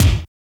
GRUNGE KICK.wav